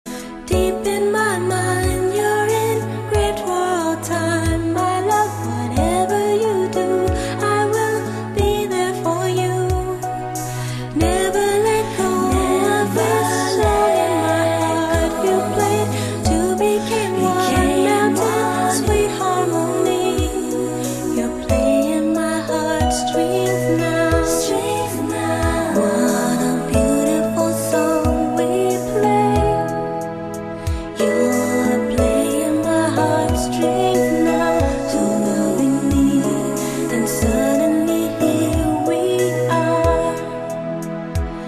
M4R铃声, MP3铃声, 欧美歌曲 82 首发日期：2018-05-15 18:48 星期二